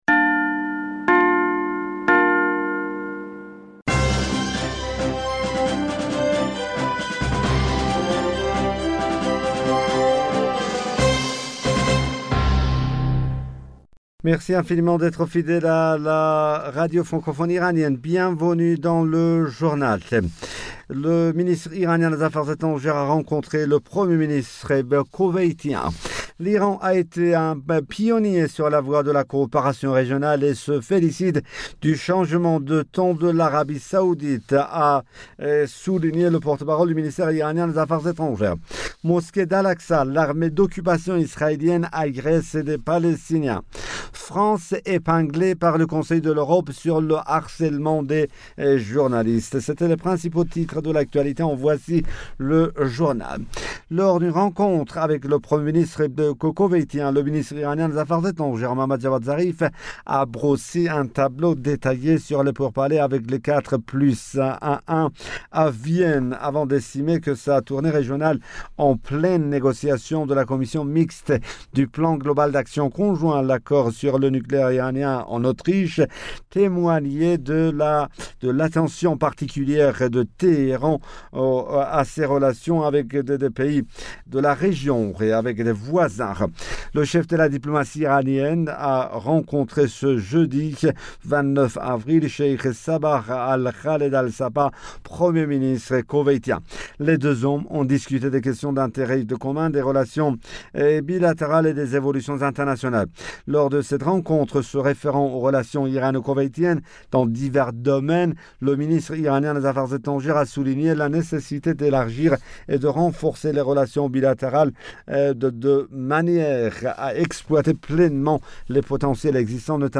Bulletin d'information du 30 Avril 2021